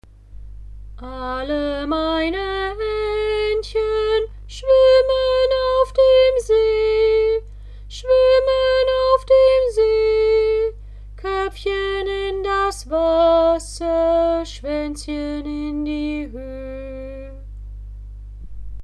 Würde ich „Alle meine Entchen“ so singen, wie du es unten im Beispiel hörst, würdest du sagen, dass ich falsch singe. Die Melodie stimmt einfach nicht.
Sofort-besser-klingen-Alle-meine-Entchen-pentatonisch.mp3